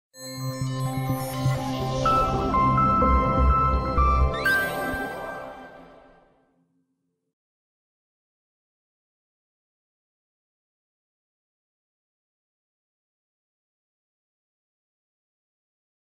LG Optimus 2X (SU660) sound effects free download